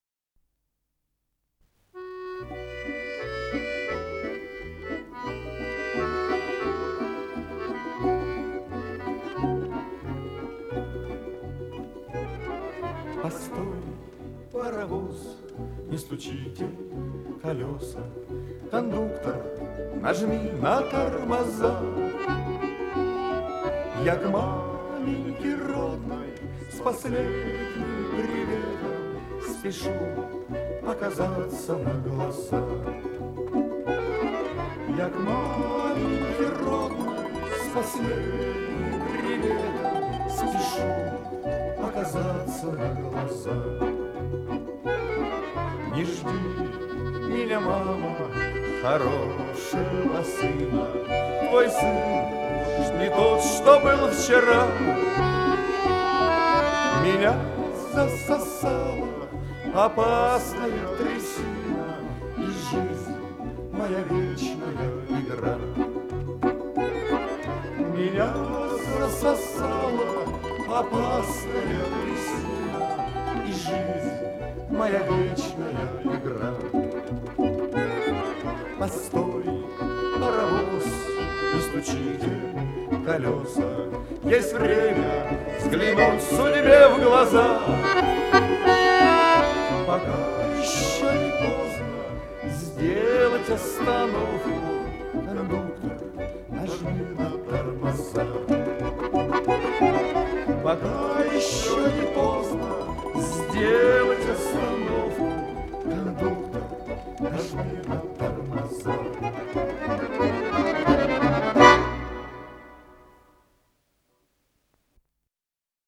с профессиональной магнитной ленты
Авторы версииГеннадий Заволокин - обработка
ИсполнителиМужская группа ансамбля "Частушка"
Дирижёр - Геннадий Заволокин
Скорость ленты38 см/с